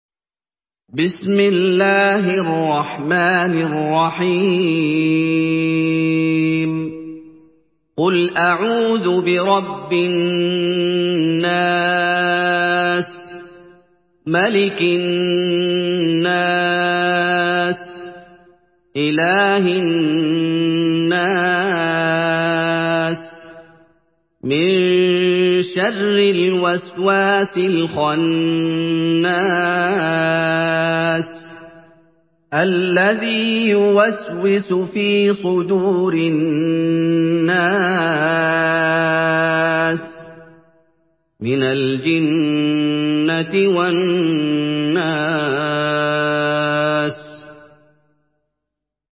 Surah Nas MP3 by Muhammad Ayoub in Hafs An Asim narration.
Murattal Hafs An Asim